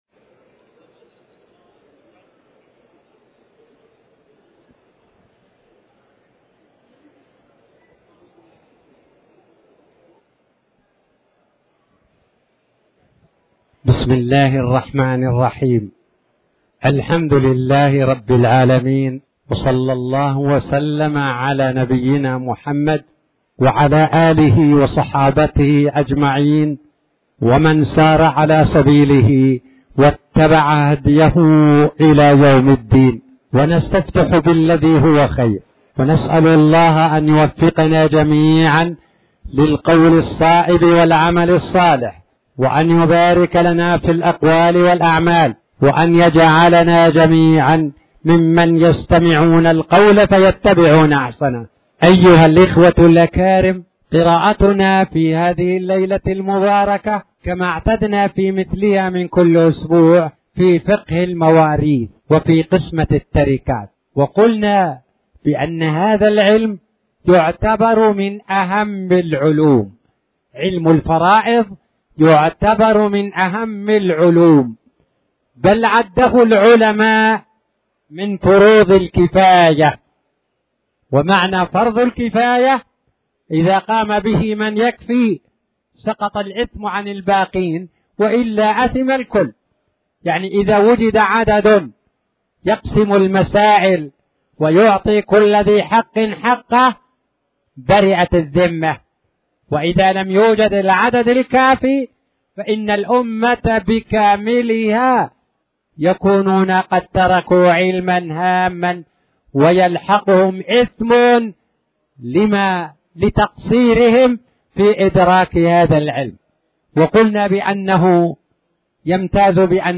تاريخ النشر ٢ جمادى الأولى ١٤٣٨ هـ المكان: المسجد الحرام الشيخ